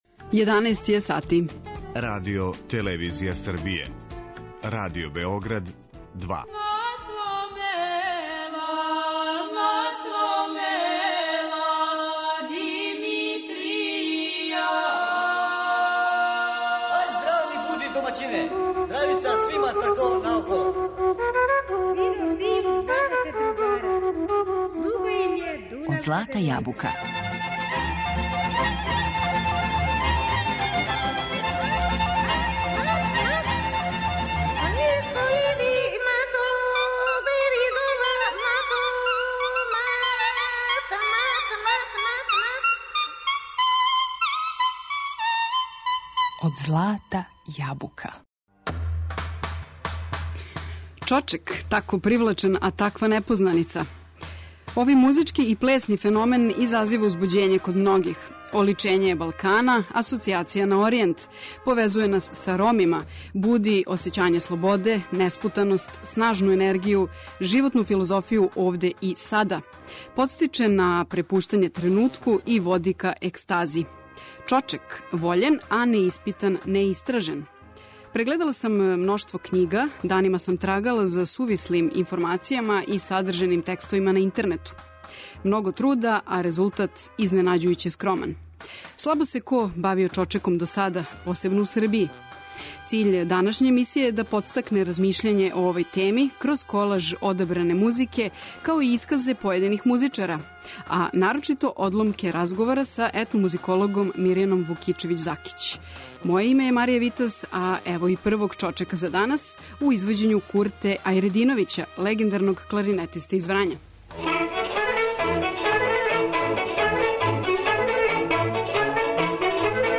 Циљ емисије је да подстакне размишљање о овој теми, кроз колаж одабране музике, исказе појединих музичара, а нарочито одломке разговора са етномузикологом